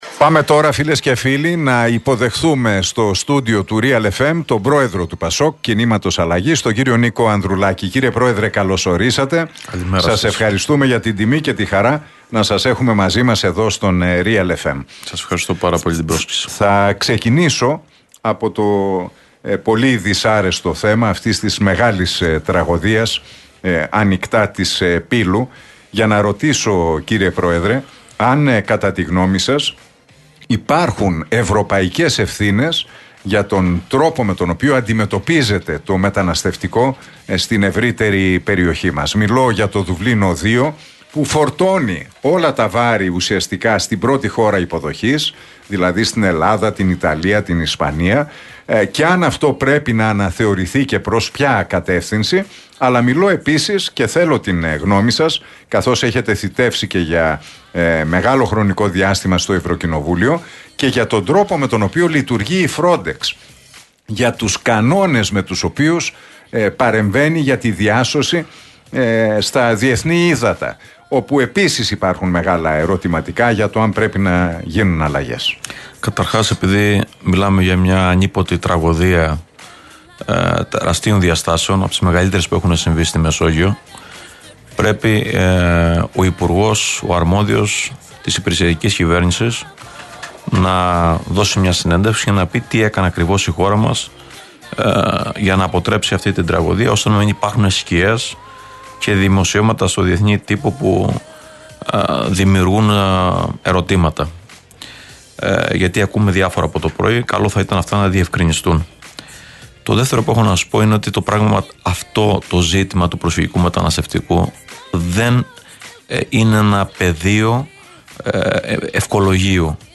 Ο Νίκος Ανδρουλάκης παραχώρησε εφ'όλης της ύλης συνέντευξη στην εκπομπή του Νίκου Χατζηνικολάου στον Realfm 97,8.